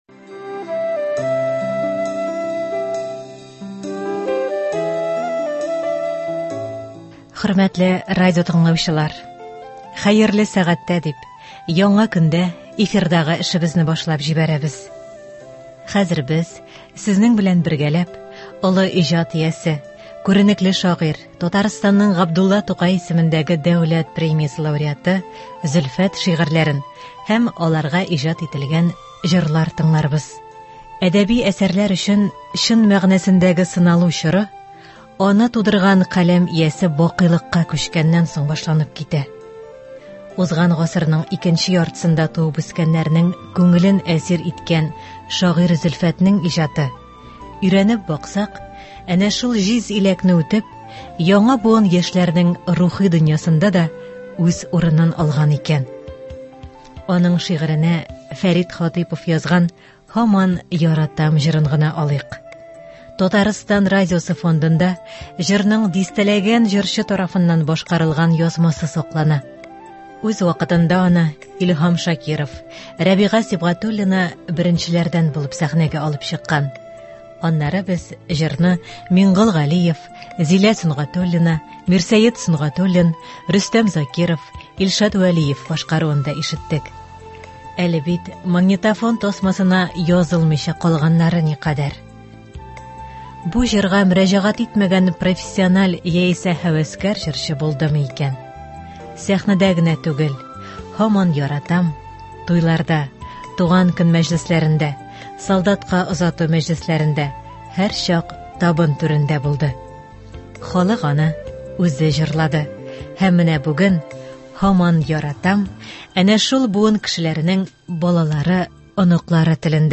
Шагыйрь Зөлфәт әсәрләреннән әдәби-музыкаль композиция.